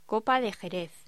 Locución: Copa de jerez
voz